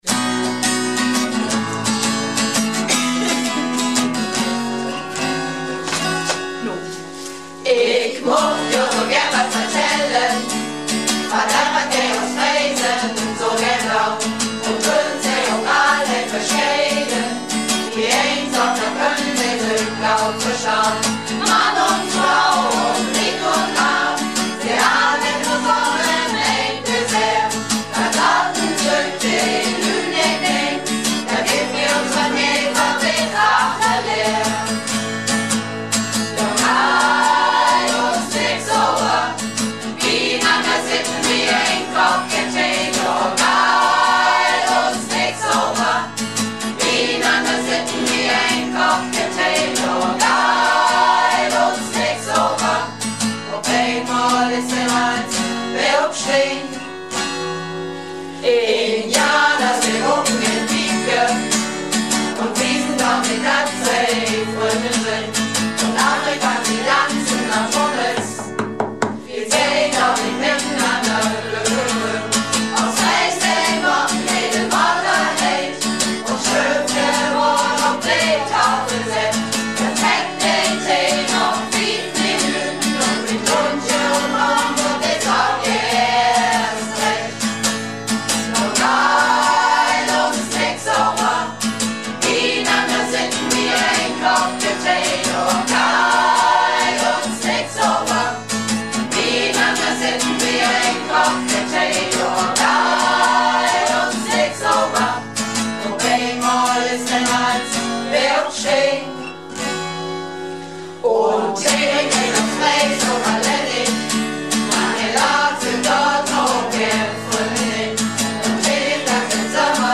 Nur mit Gitarre